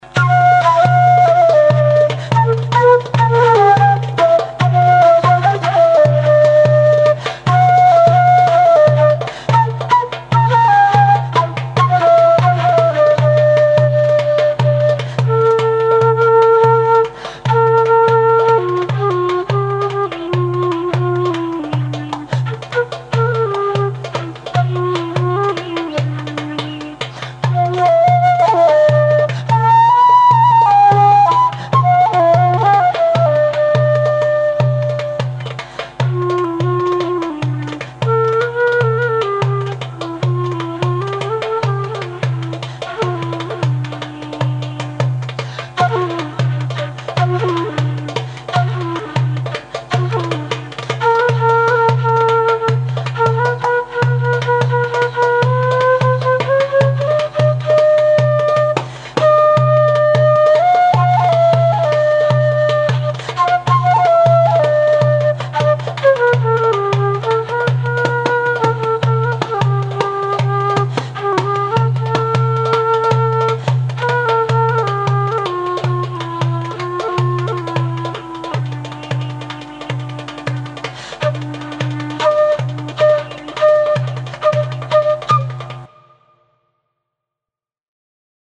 flauta travesera y darbuka   frula
flauta tradicional balcánica